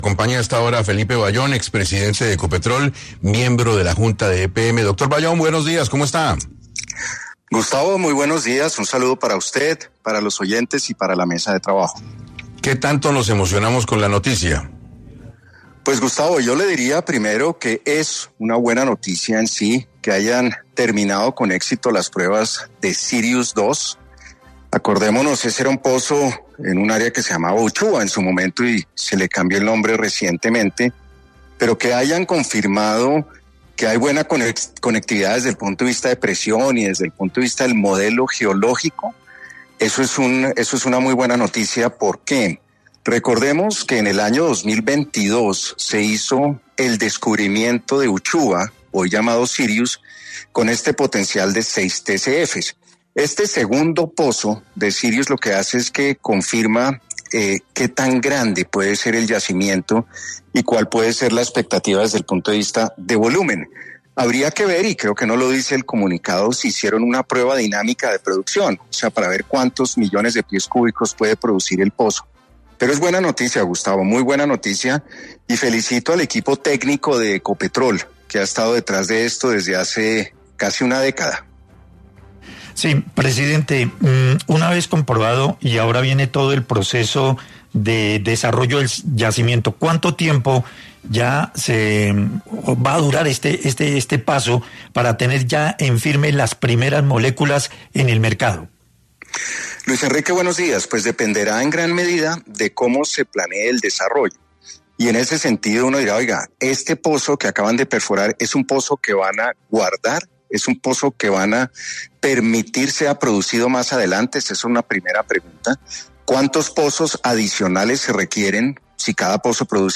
En entrevista para 6AM, Felipe Bayón, expresidente de Ecopetrol, habló sobre la terminación de las pruebas iniciales de formación del pozo Sirius-2 y cuál sería la cantidad de recursos que la reserva puede proveer a los colombianos